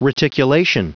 Prononciation du mot reticulation en anglais (fichier audio)
Prononciation du mot : reticulation